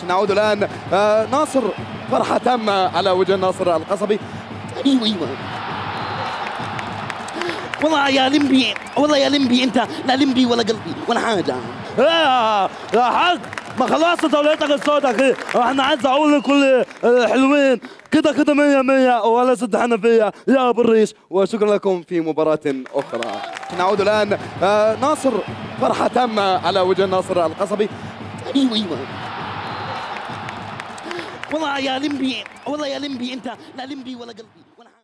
ترفيهى